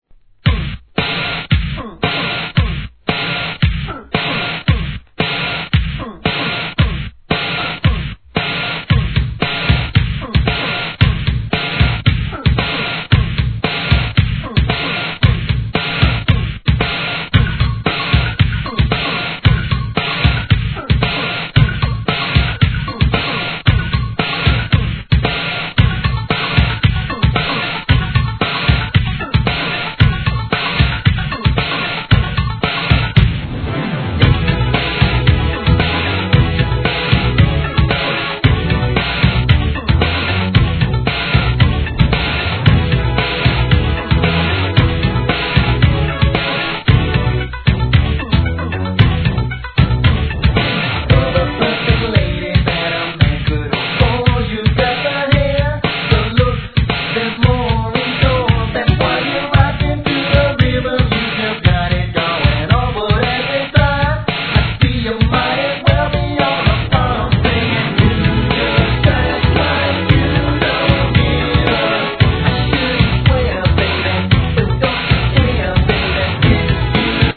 HIP HOP/R&B
本作は跳ねたFUNKYなBEATがNEW JACK SWINGにも通ずる逸品!